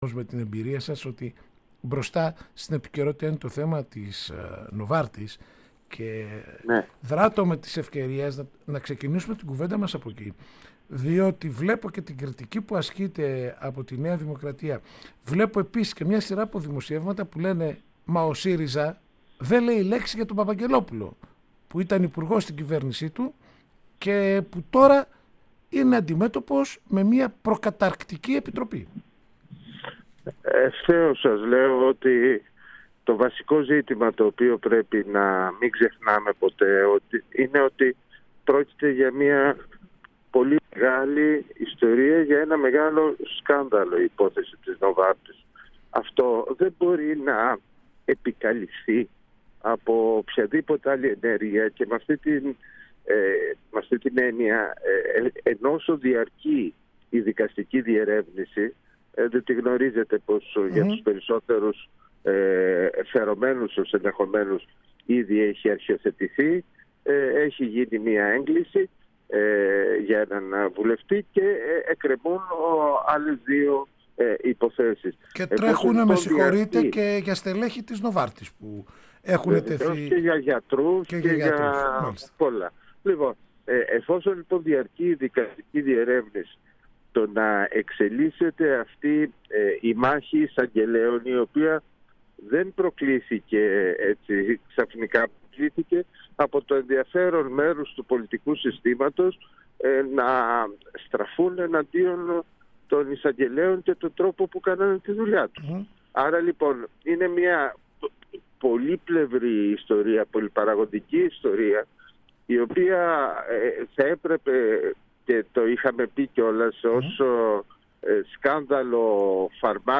Στο επίκεντρο της σημερινής συνεδρίασης βρέθηκε ο οδικός χάρτης προς το συνέδριο και οι τελευταίες πολιτικές εξελίξεις, με φόντο την υπόθεση Novartis, για την οποία μίλησε στην ΕΡΤ και στο Πρώτο Πρόγραμμα ο βουλευτής του ΣΥΡΙΖΑ και τέως πρόεδρος της Βουλής, Νίκος Βούτσης.
ΝΙΚΟΣ-ΒΟΥΤΣΗΣ-ΣΤΟ-ΠΡΩΤΟ-ΠΡΟΓΡΑΜΜΑ.mp3